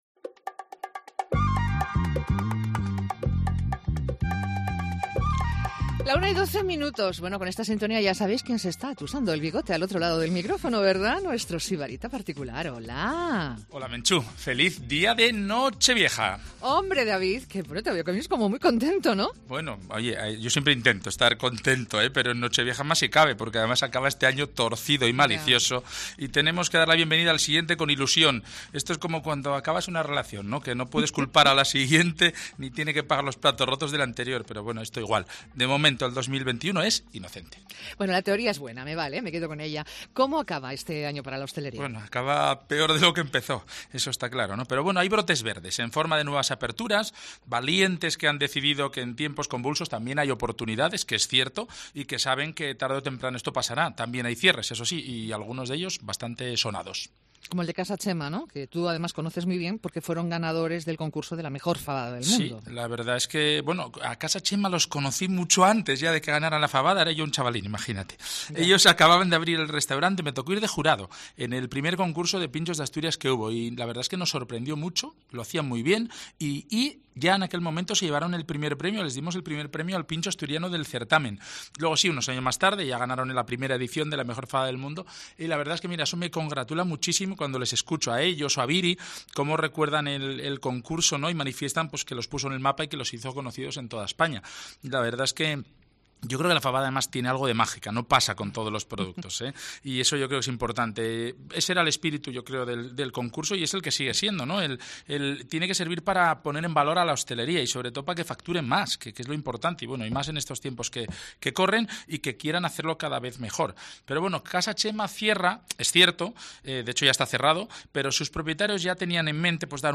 Conversamos con el recientemente elegido mejor cocinero del país, Nacho Manzano.